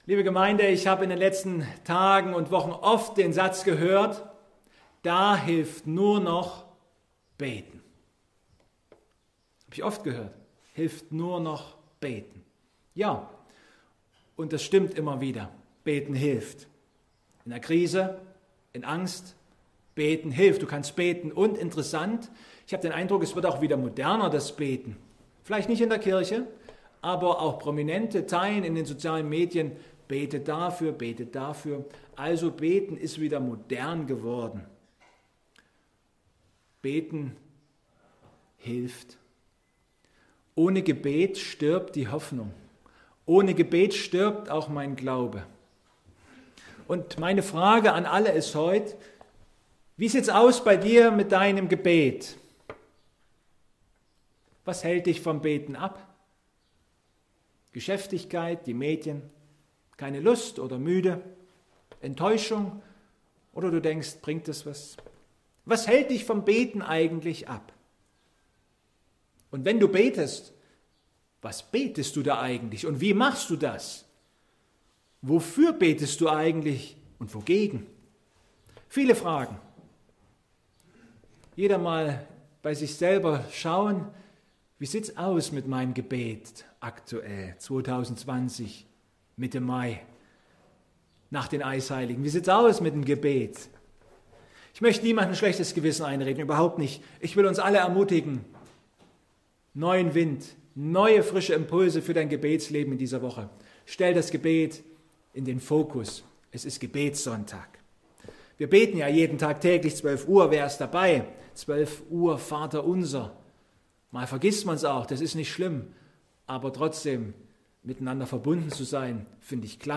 Mt 6,5-8 Gottesdienstart: Online-Gottesdienst Heute ist der internationale Weltfernmeldetag.